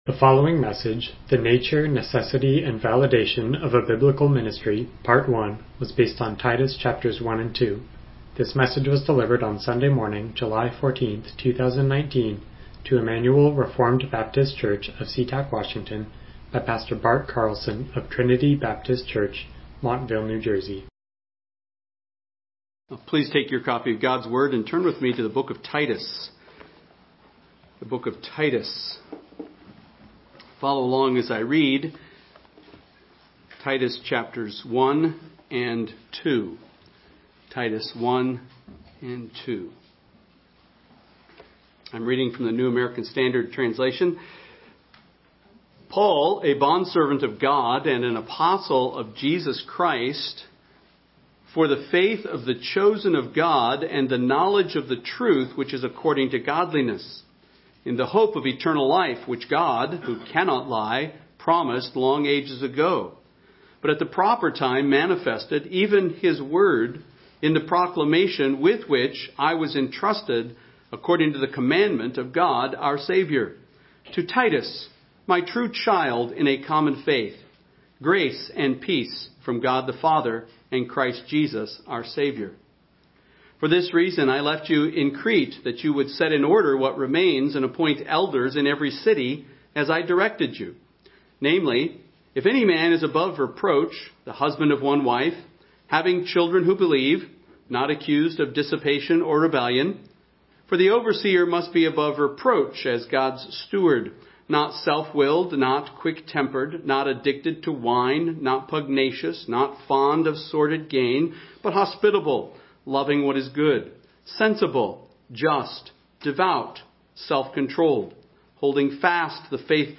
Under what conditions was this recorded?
Passage: Titus 1:1-2:15 Service Type: Morning Worship